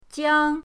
jiang1.mp3